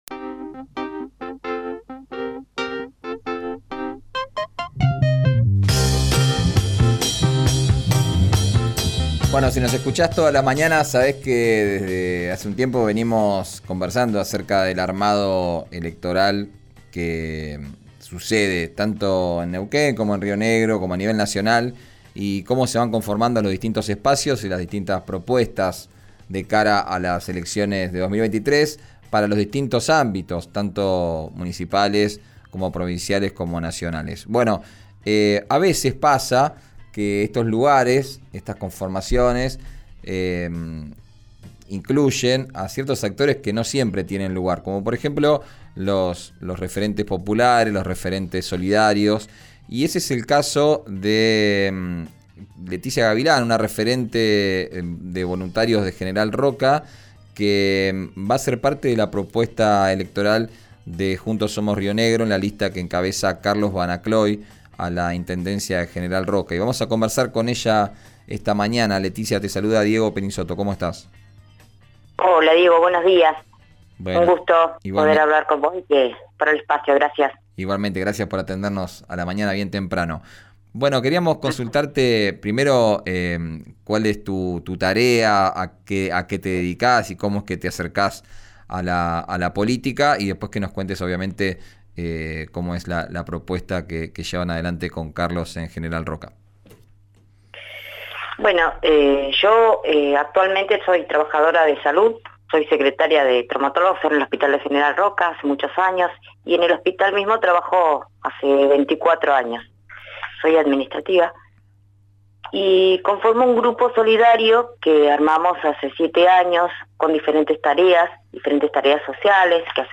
en «Arranquemos», por RÍO NEGRO RADIO.